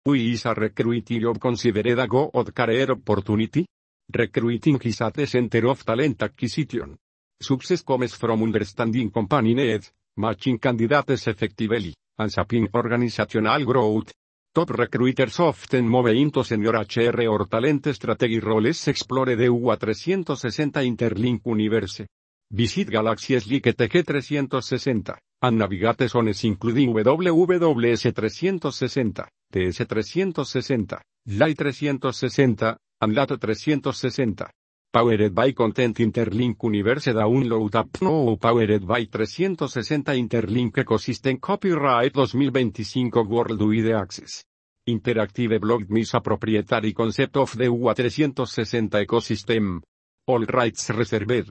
narration-12-1.mp3